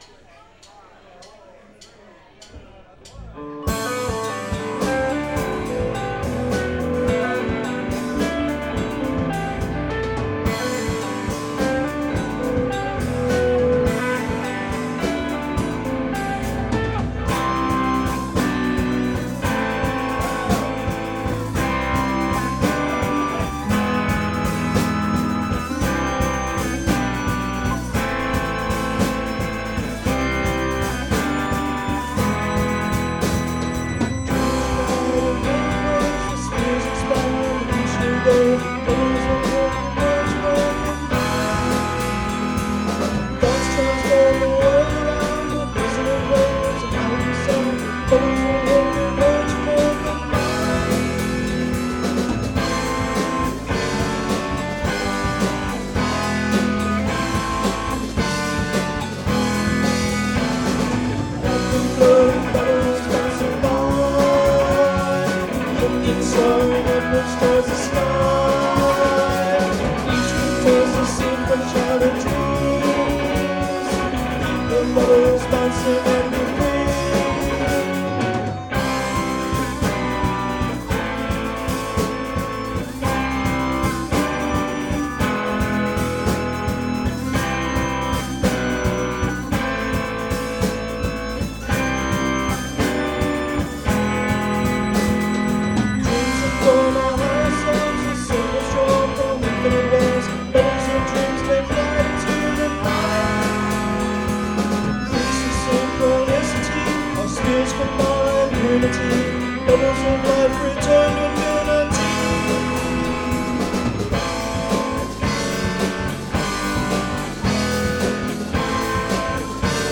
guitar
bass
keyboards
malletKAT
drums